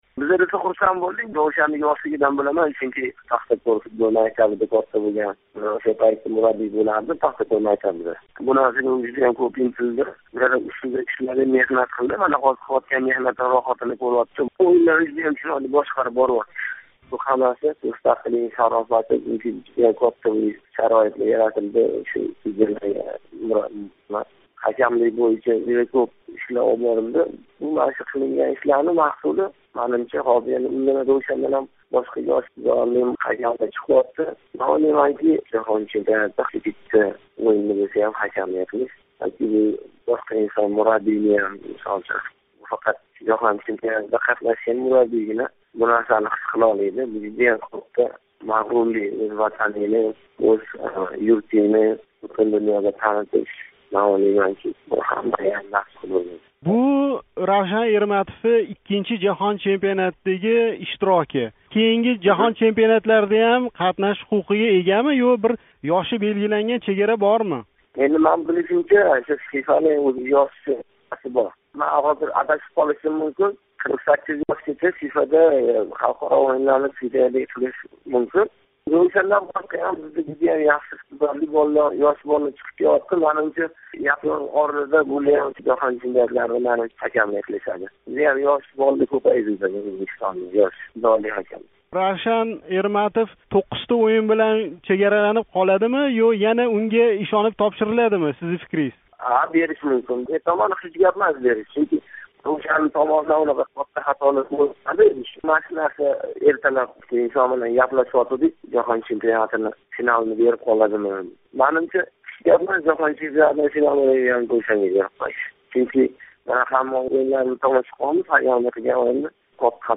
суҳбатни